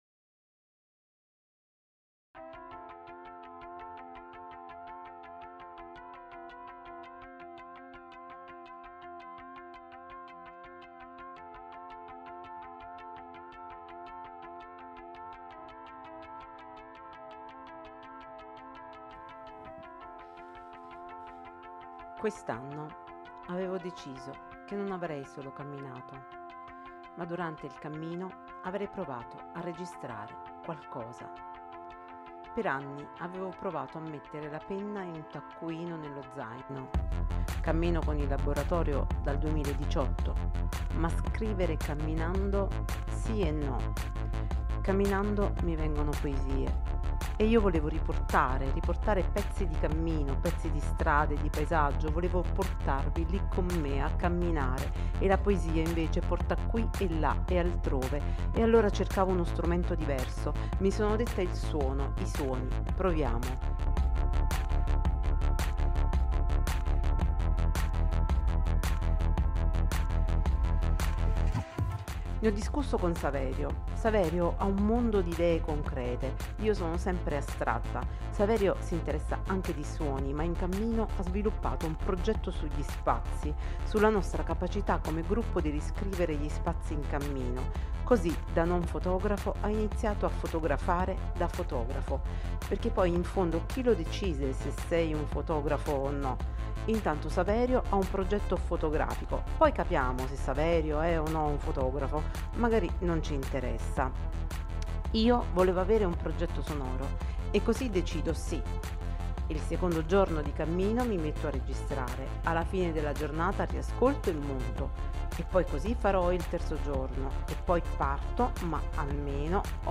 Questo mi sono chiesta e ho cercato di rispondere a questa domanda in questo racconto sonoro.
L’esercizio ha avuto luogo in occasione della Summer School del Laboratorio del Cammino WOW Walking on the Wire dal 27 al 30 agosto. Si tratta di un esercizio per iniziare a muoversi nello spazio invisibile dei suoni in cammino. Il materiale è stato registrato con uno ZOOMH1N.